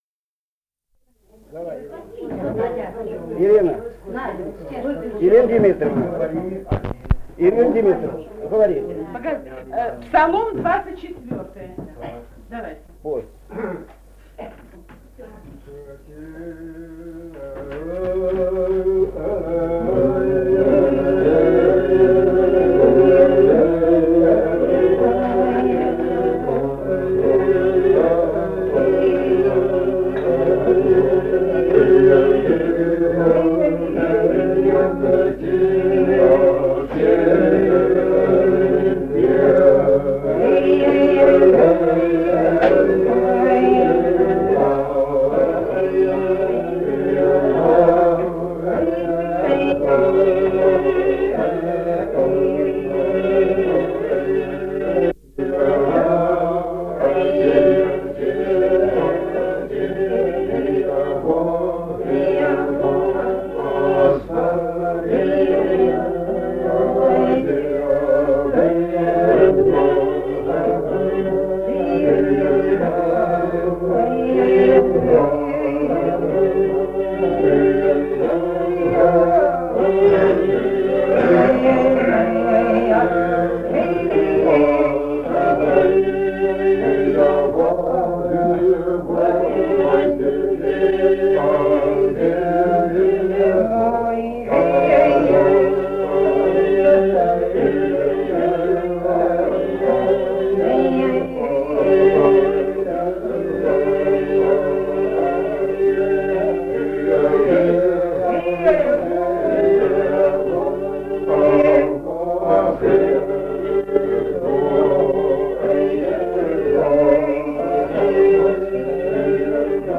полевые материалы
Азербайджан, г. Баку, 1971 г.